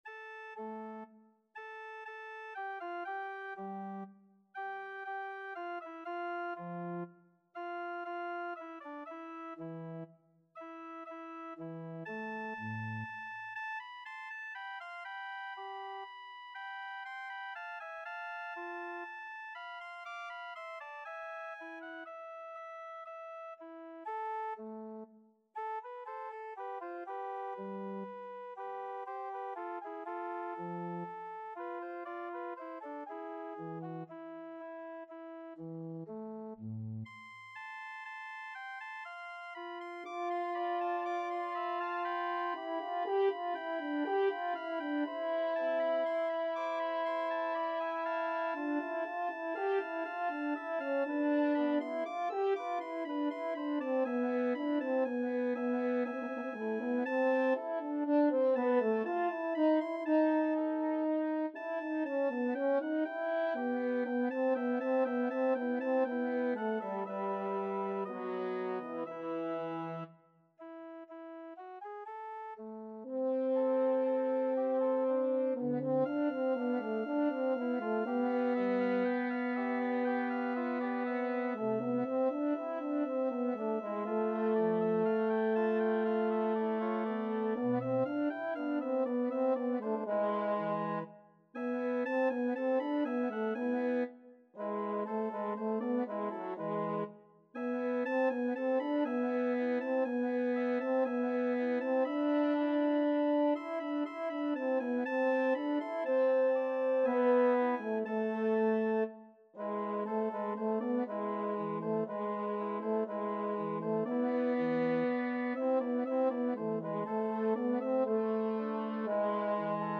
3/4 (View more 3/4 Music)
Classical (View more Classical French Horn Music)